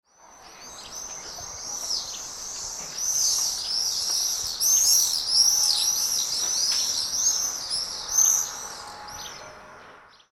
Swallows Chirping In Flight Sound Effect
Description: Swallows chirping in flight sound effect. Authentic field recording captured in Greece during the early morning of warm summer months.
Swallows-chirping-in-flight-sound-effect.mp3